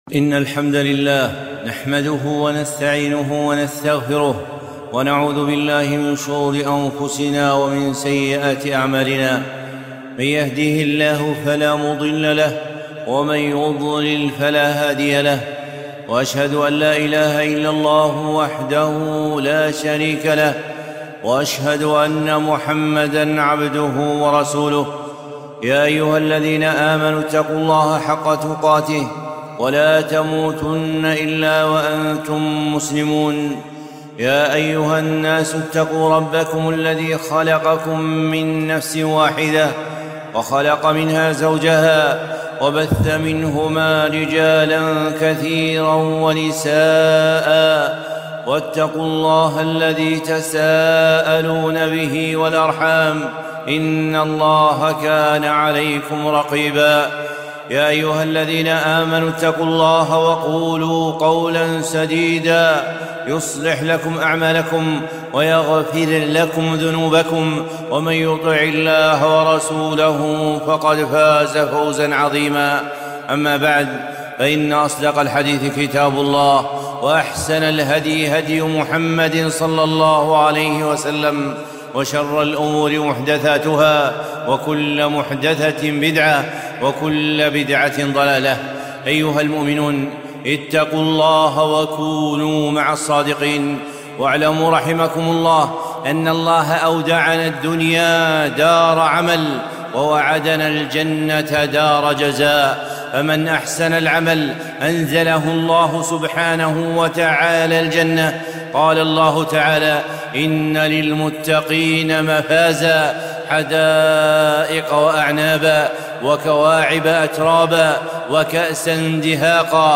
خطبة - أحصوها تنالوا الجنة